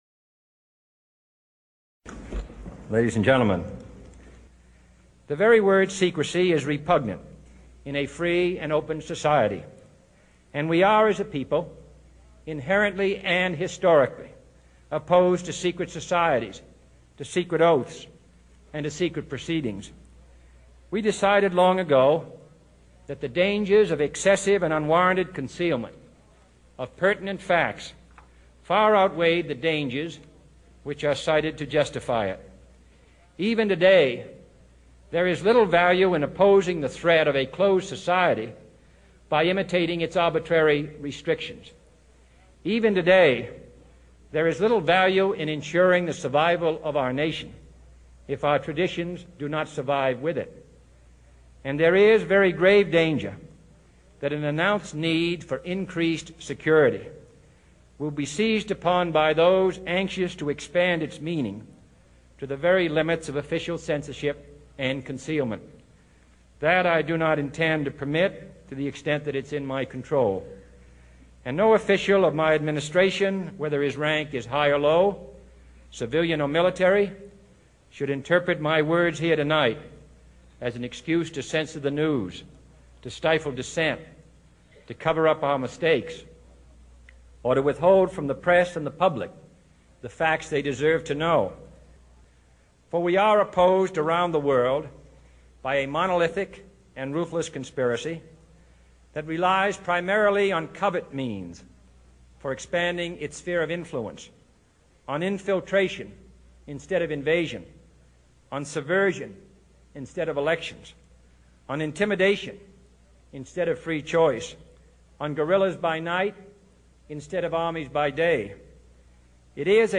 JFK:n puhe salaseuroihin ja mediaan liittyen